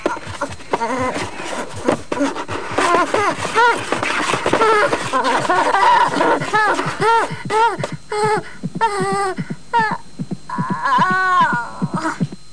home *** CD-ROM | disk | FTP | other *** search / Horror Sensation / HORROR.iso / sounds / iff / nastyo1.snd ( .mp3 ) < prev next > Amiga 8-bit Sampled Voice | 1992-12-21 | 250KB | 1 channel | 19,886 sample rate | 12 seconds